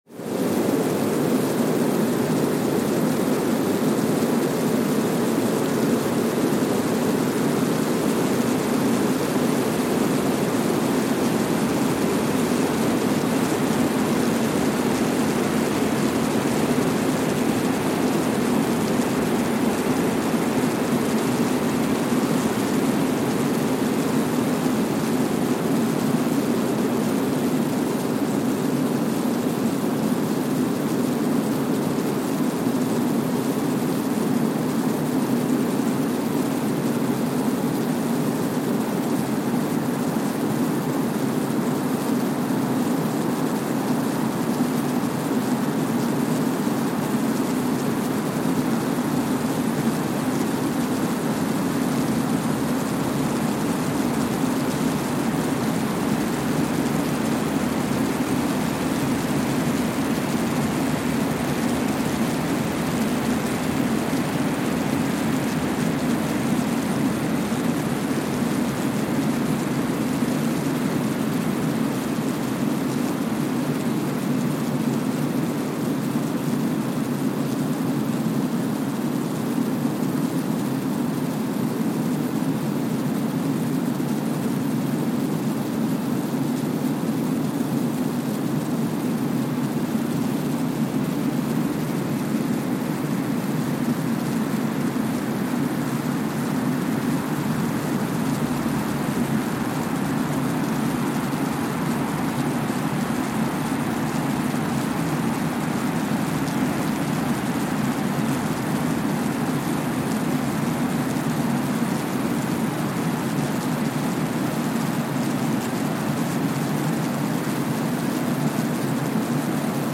Sensor : Streckeisen STS-5A Seismometer
Speedup : ×1,000 (transposed up about 10 octaves)
Gain correction : 25dB
SoX post-processing : highpass -2 90 highpass -2 90